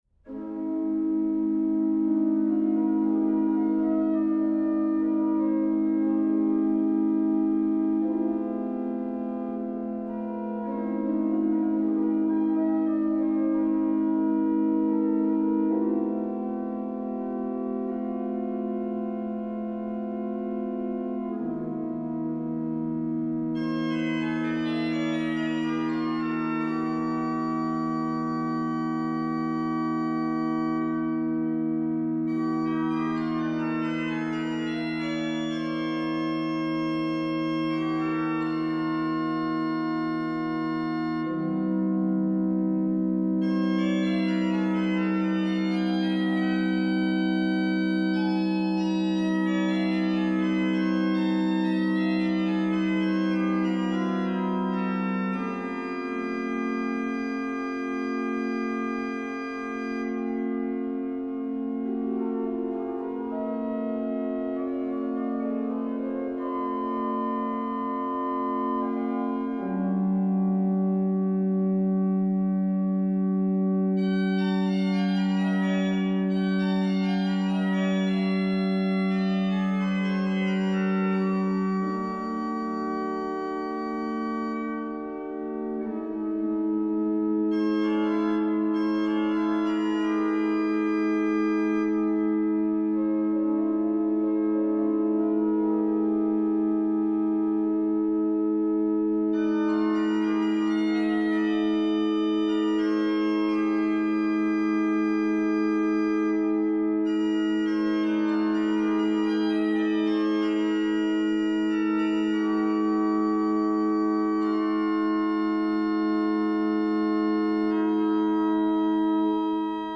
for Organ (manuals only)2 min.